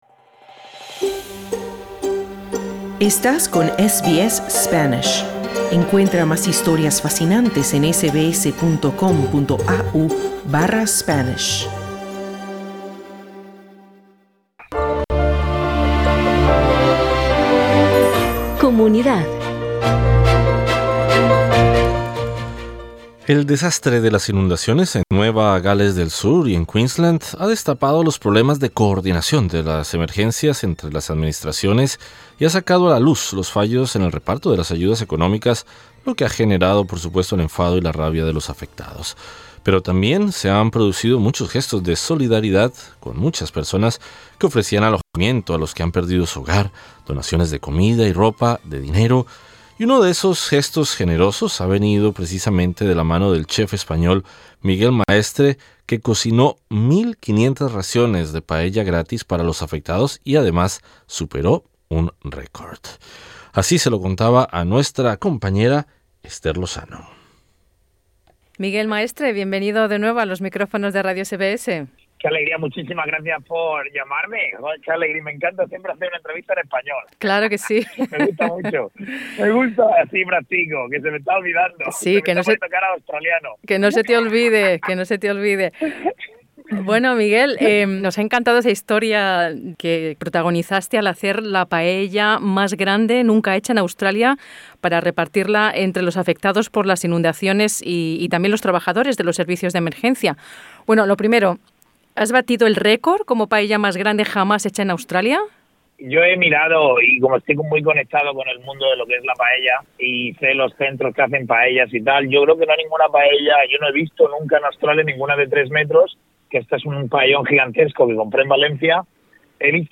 El “celebrity chef” Miguel Maestre preparó la paella más grande hecha hasta el momento en Australia para repartirla entre los afectados por las inundaciones y los trabajadores de los servicios de emergencia. La estrella de la televisión australiana cuenta a SBS Spanish qué le llevó a cocinar las 1.500 raciones de paella, gracias también a donaciones de empresas y particulares.